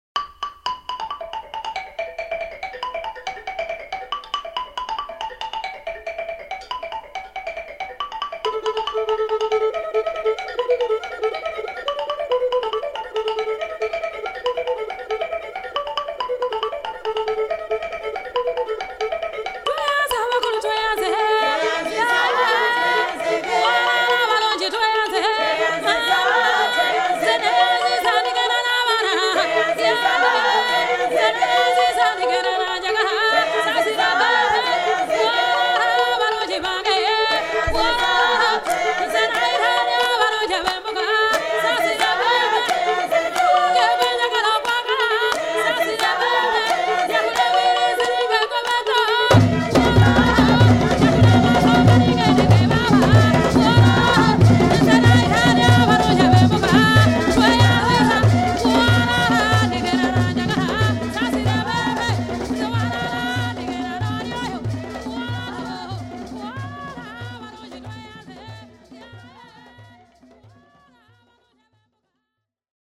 • Melodies and rhythms from across Africa
Hailing from various parts of Surrey and Greater London, this energetic drumming and dancing troupe stage fascinating displays of traditional African rhythms, songs and dances.